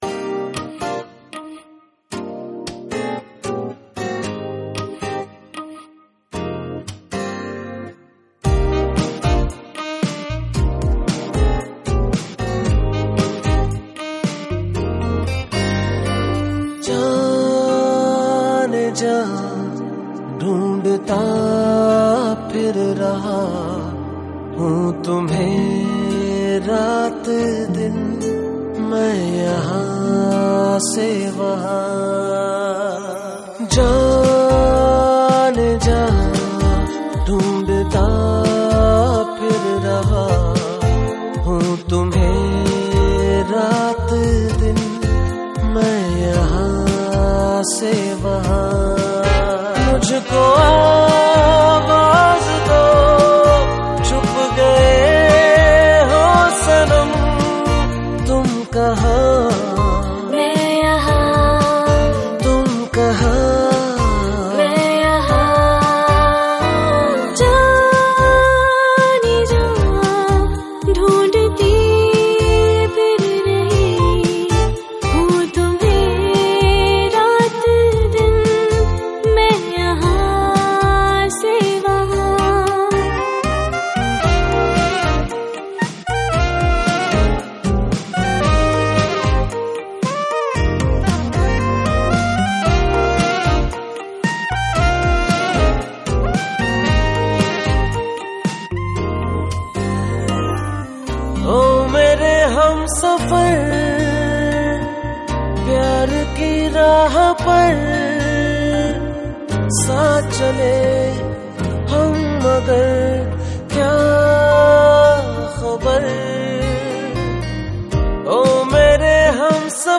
Pop Songs
> 64 Kbps Low Quality